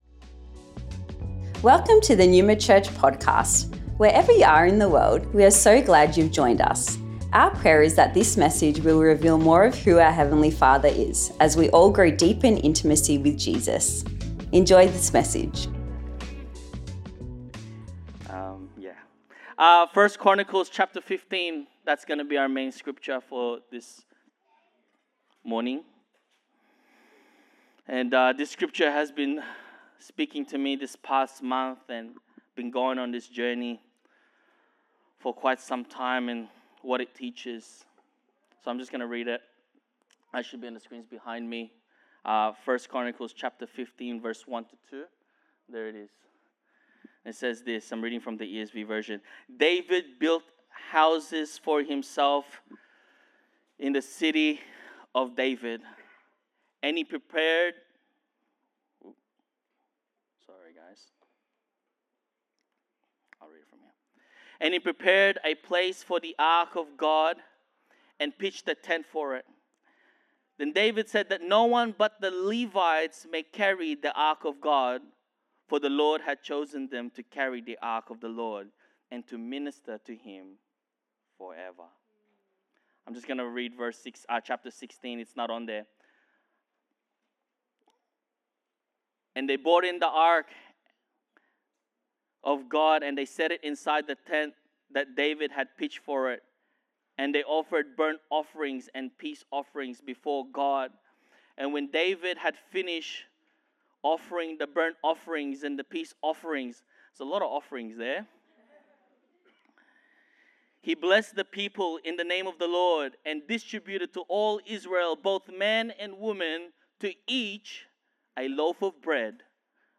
Originally recorded at Neuma Melbourne West on the 16th March 2025